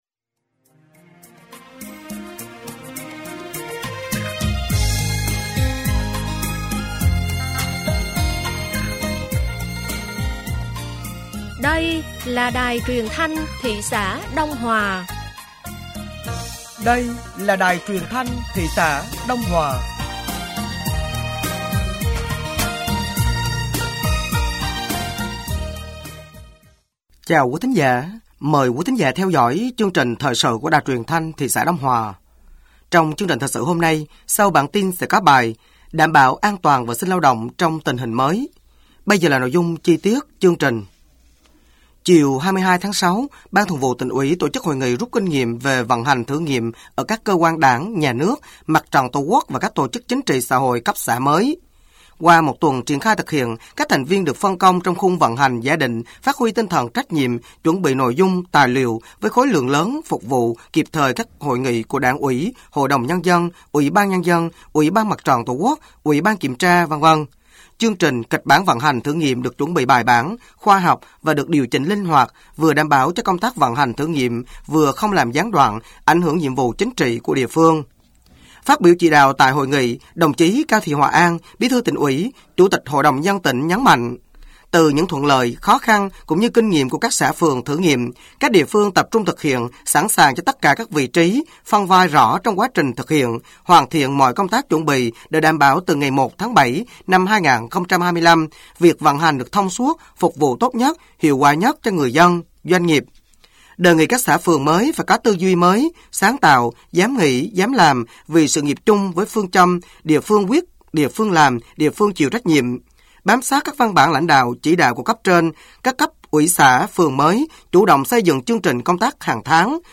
Thời sự tối ngày 23/6 sáng ngày 24/6/2025